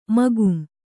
♪ magum